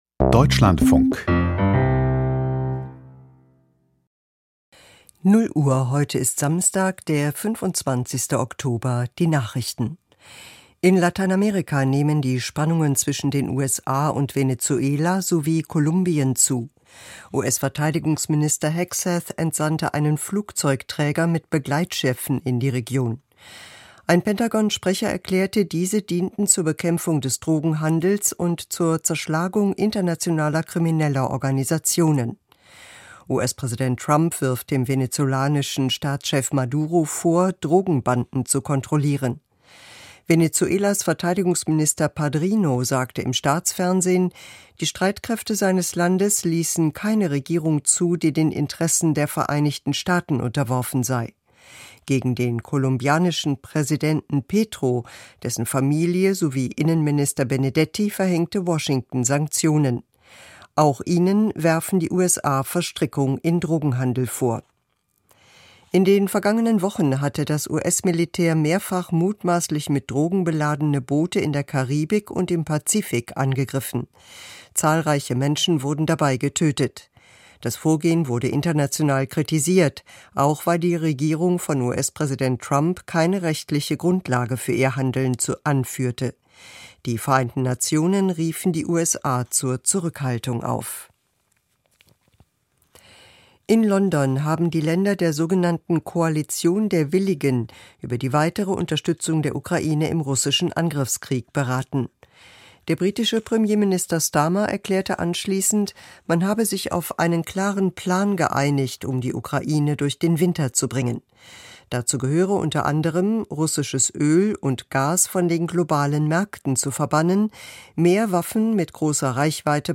Die Nachrichten vom 25.10.2025, 00:00 Uhr
Die wichtigsten Nachrichten aus Deutschland und der Welt.